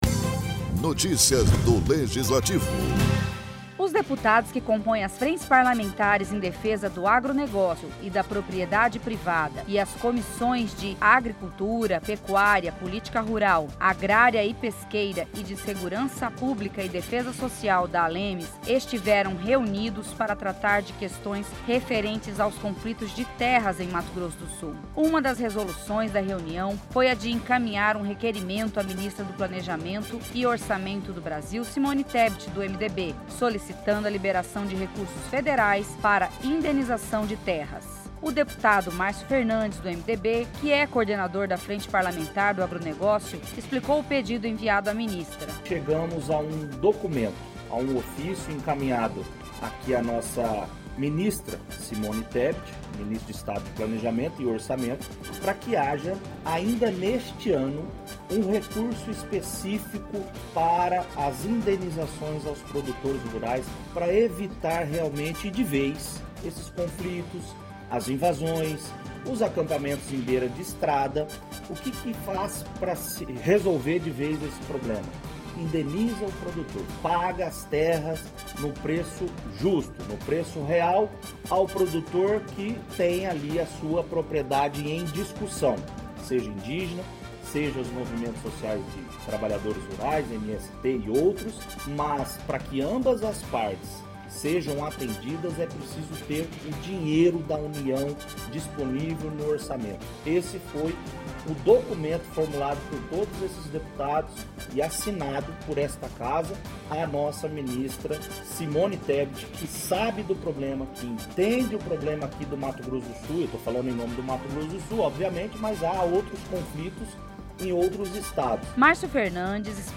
Frente enviou requerimento a Ministra do Planejamento e Orçamento do Brasil, Simone Tebet (MDB), solicitando a liberação de recursos federais para indenização de terras. O deputado Marcio Fernandes (MDB) que é coordenador da Frente Parlamentar do Agronegócio explicou o pedido enviado a Ministra.